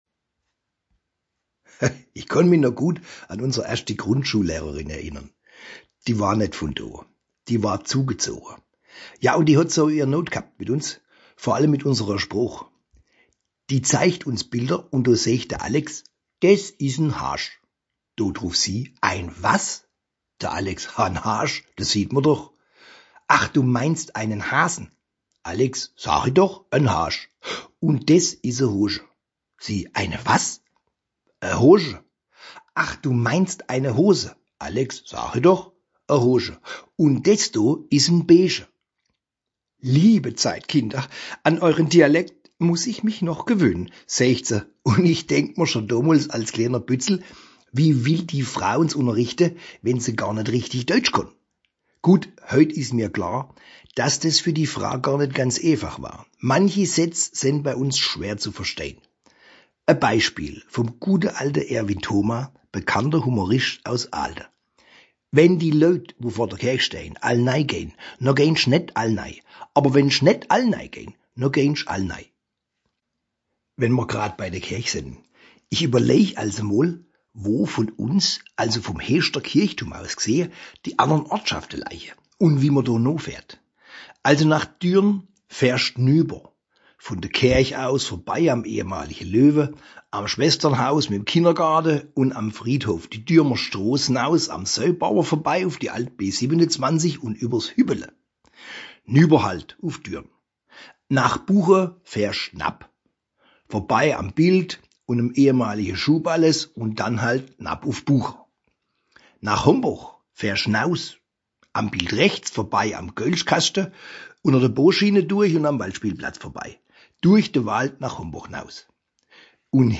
Mundart Heeschter Dialekt (anklicken)
Mundart Hainstadt - Richtig Doeutsch_01.mp3